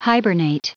Prononciation du mot hibernate en anglais (fichier audio)
Prononciation du mot : hibernate